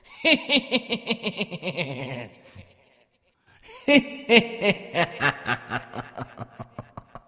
Звуки злодея
Хитрый смех сказочного злодея